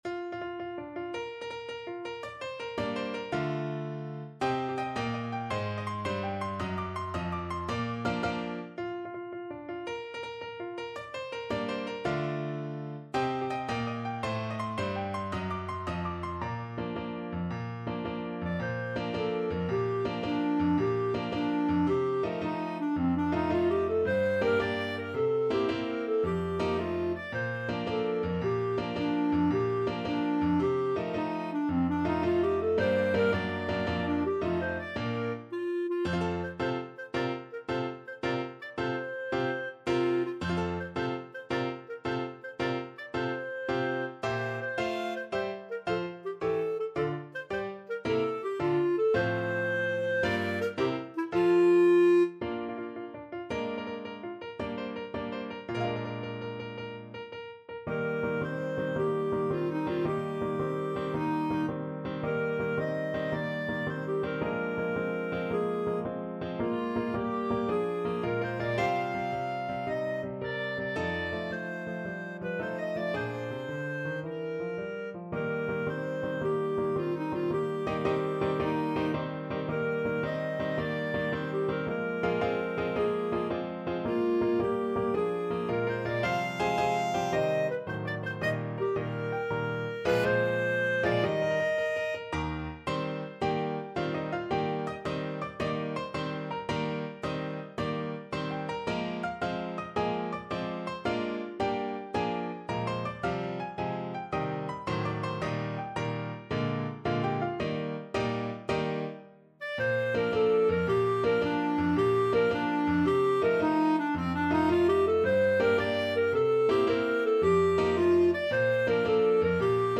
Clarinet version
6/8 (View more 6/8 Music)
March .=c.110
Classical (View more Classical Clarinet Music)